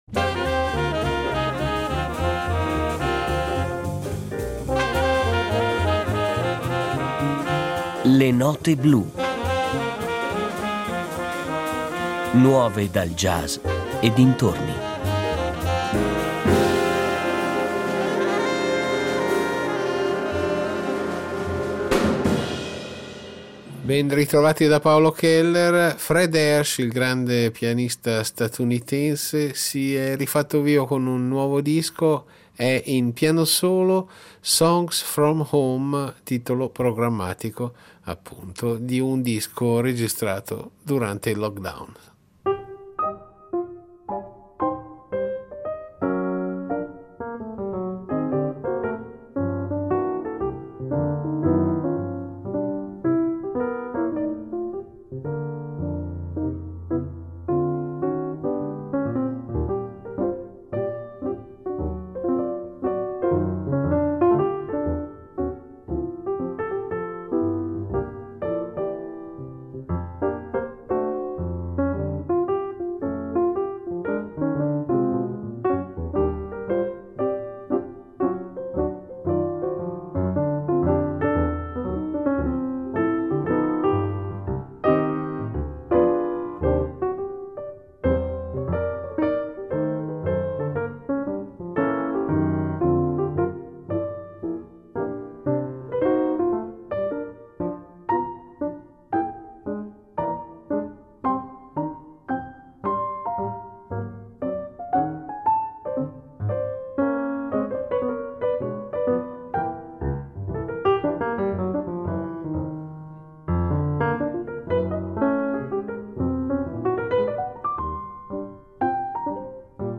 il piano solo